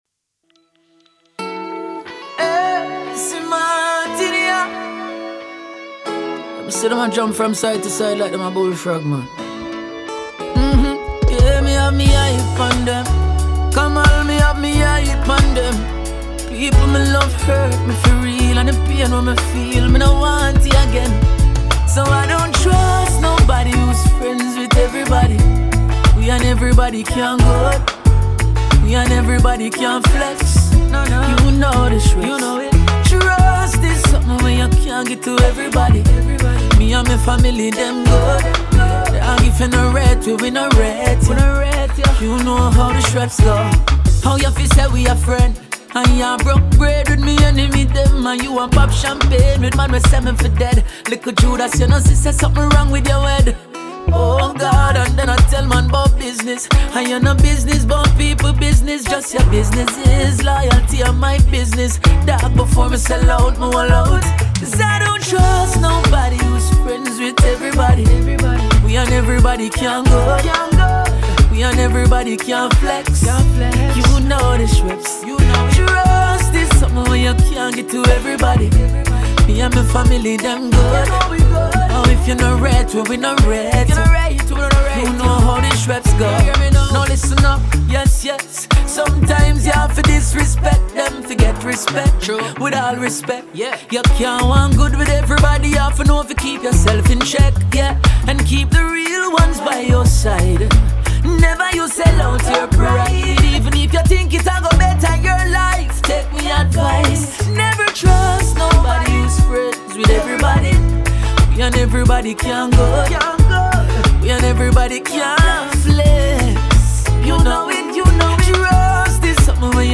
reggae et du dancehall